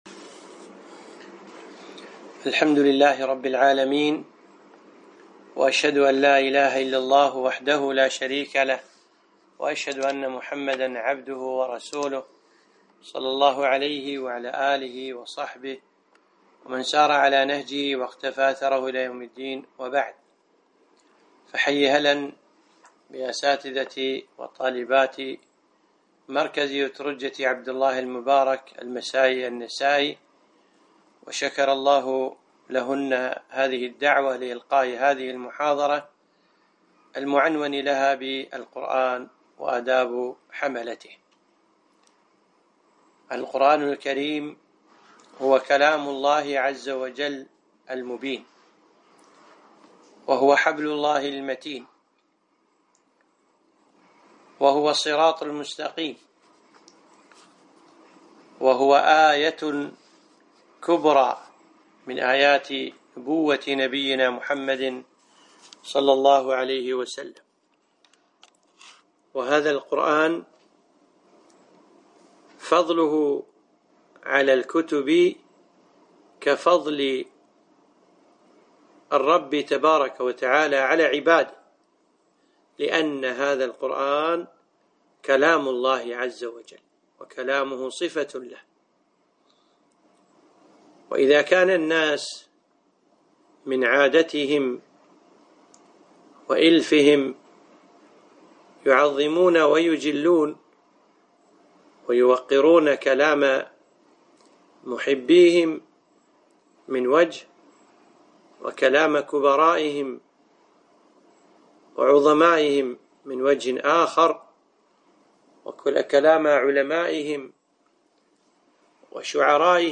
محاضرة - القرآن وآداب حملته